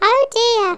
OhDear.wav